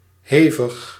Ääntäminen
Synonyymit songé long anus Ääntäminen Tuntematon aksentti: IPA: /pʁɔ.fɔ̃/ Haettu sana löytyi näillä lähdekielillä: ranska Käännös Ääninäyte Adjektiivit 1. diep 2. diepgaand 3. hevig 4. ingrijpend Suku: m .